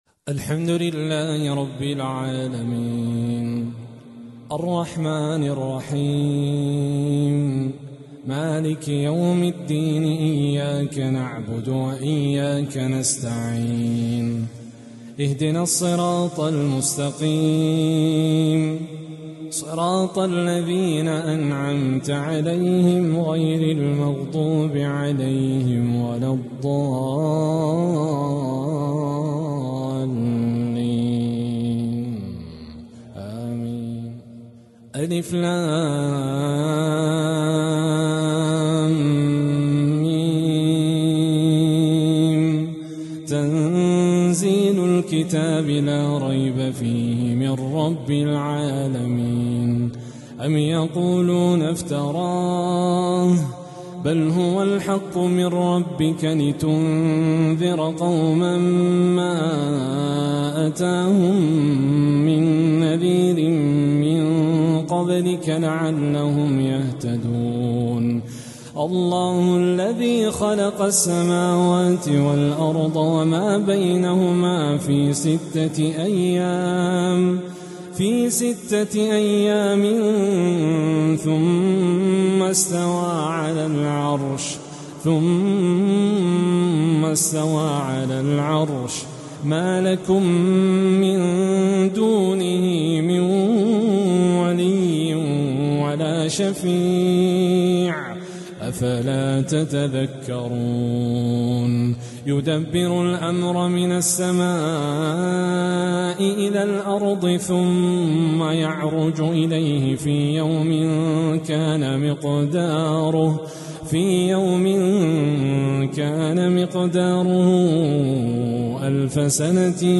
جديد - باسلوب جديد سورتي السجدة والإنسان من فجر الجمعة